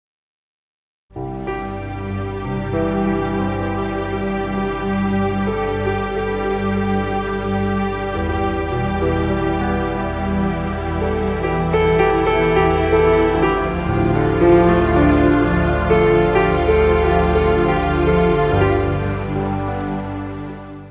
Área:  Novas Linguagens Musicais